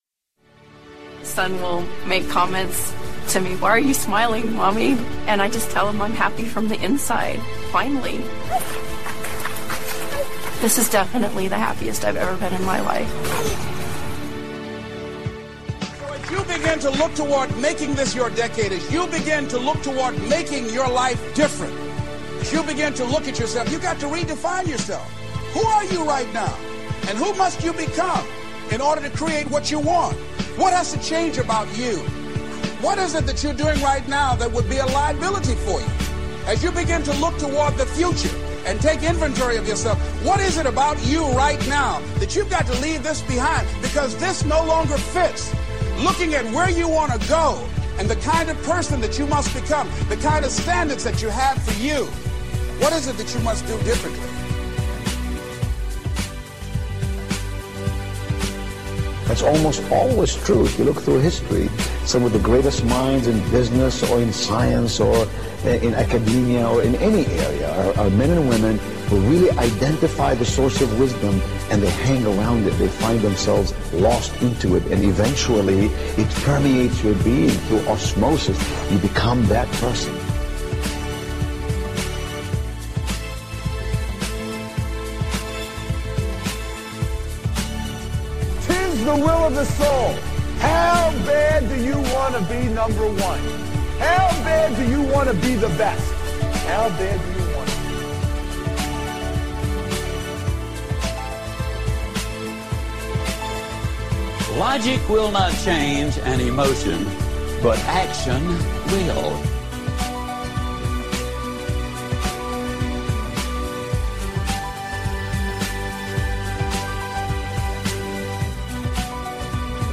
Between songs you will often hear military “jodies” which are used to maintain rhythm (aka jogging cadences) and add some MOTIVATION to exercise or to GET THINGS DONE NOW!